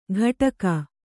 ♪ ghaṭaka